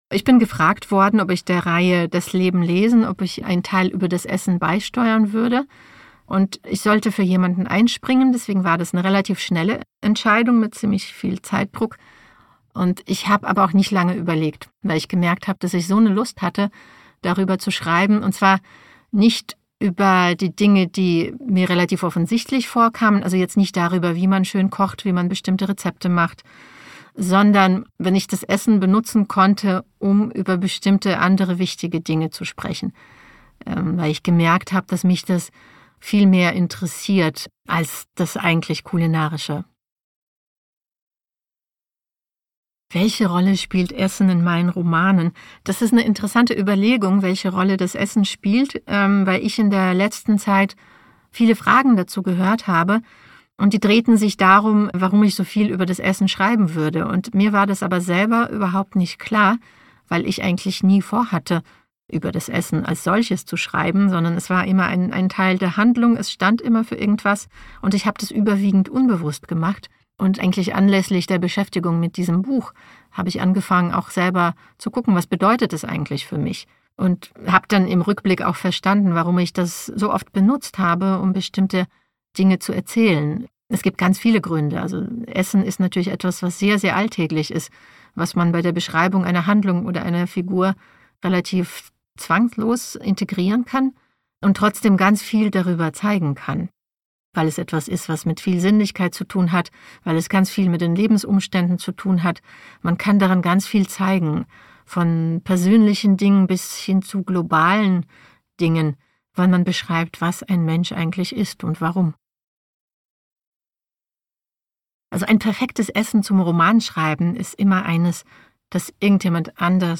Alina_Bronsky_Interview_Essen.mp3